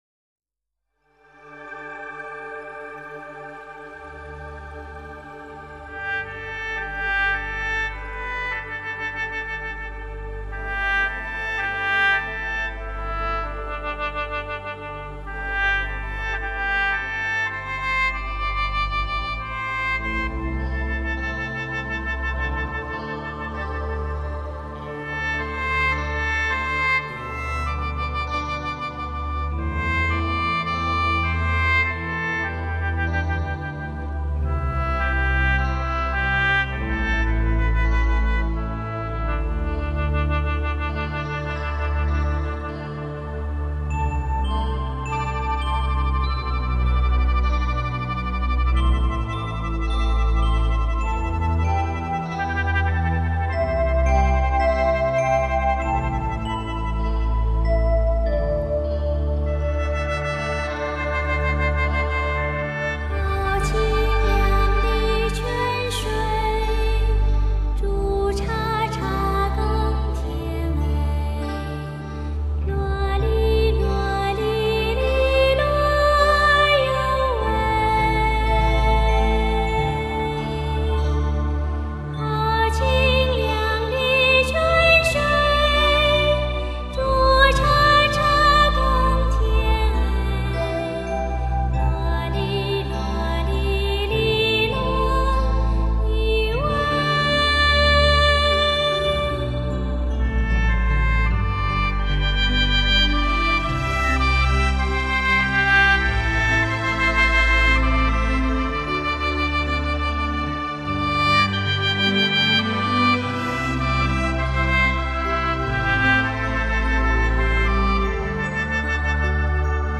婉約清揚的女聲，與空靈悠遠的笙、笛、排簫，琮流暢的柳琴、琵琶、古箏，呼應著遠方淙淙溪泉，勾勒出一幕幕深情的茶鄉記事。